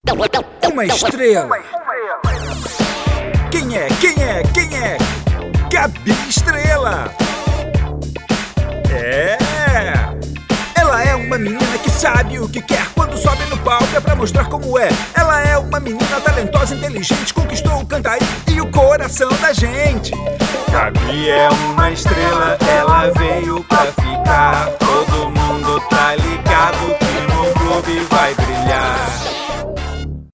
Masculino
Voz Jovem 00:30
• Tenho voz leve e versátil, e interpretação mais despojada.